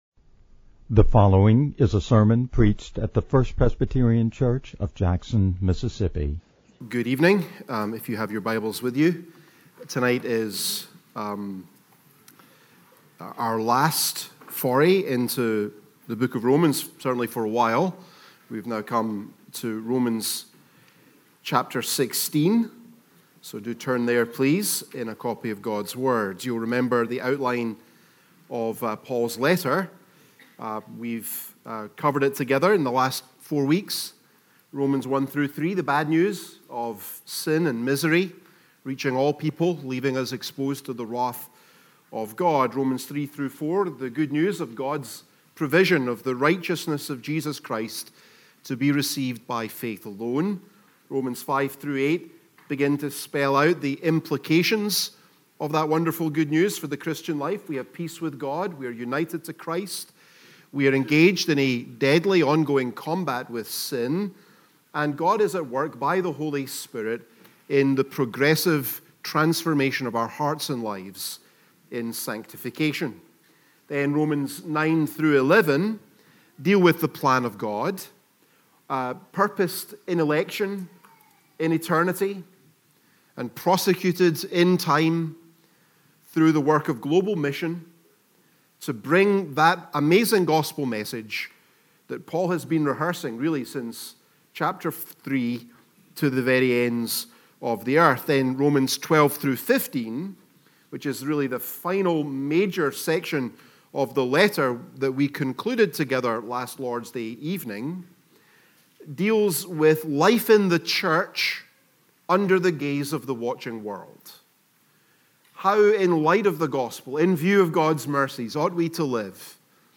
No attempt has been made, however, to alter the basic extemporaneous delivery style, or to produce a grammatically accurate, publication-ready manuscript conforming to an established style template.